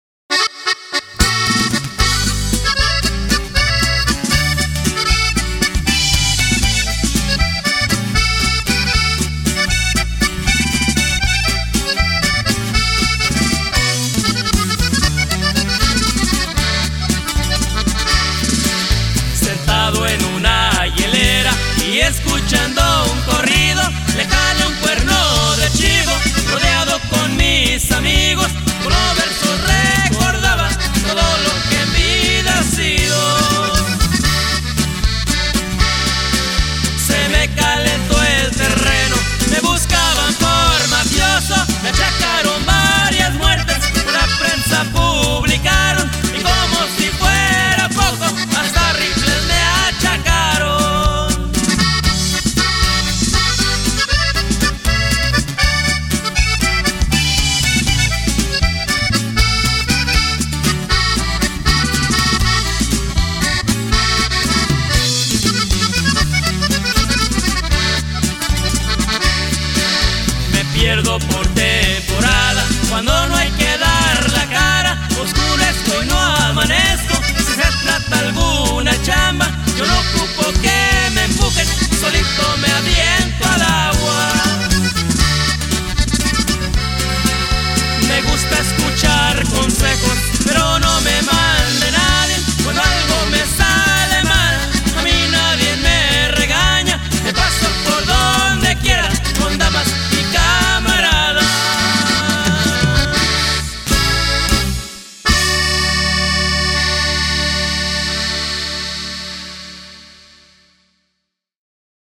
Conjunto Norteño